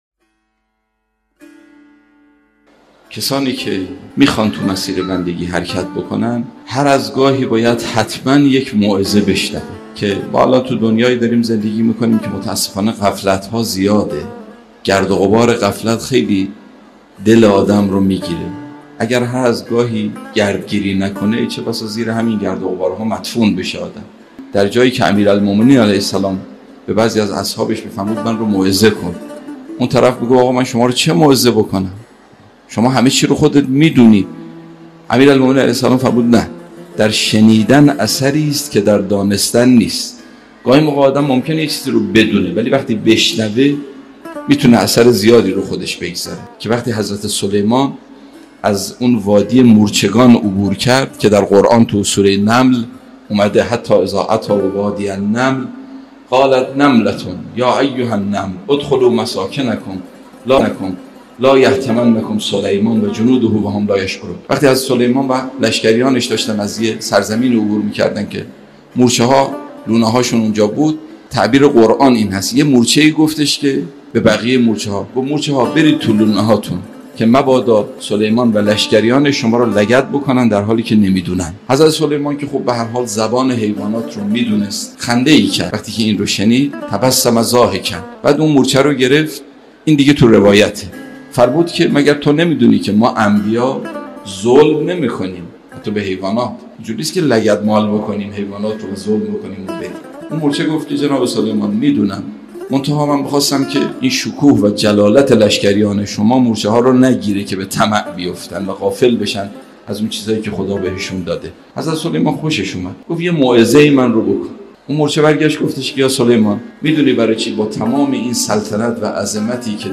موعظه و پند